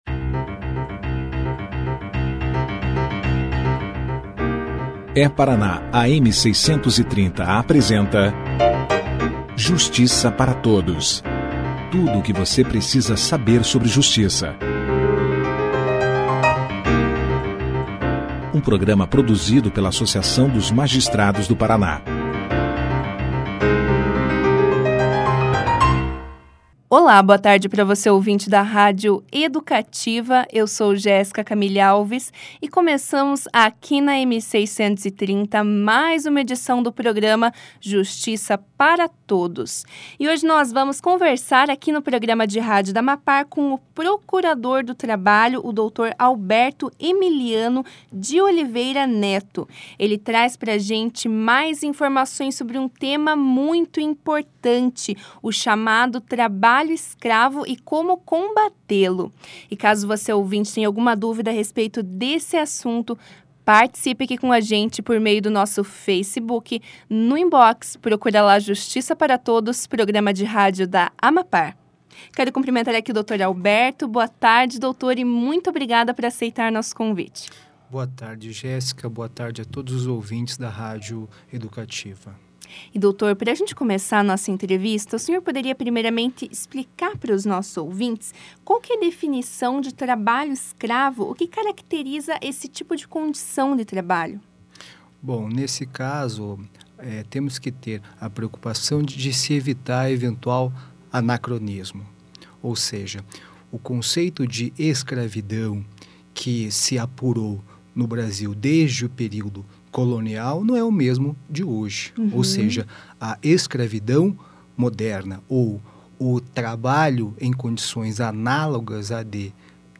Procurador fala sobre combate ao trabalho escravo
Na oportunidade, o procurador ainda traçou um panorama sobre a evolução histórica do combate ao trabalho escravo no Brasil e pontuou alguns avanços que ocorreram nos últimos anos. Confira aqui a entrevista na íntegra.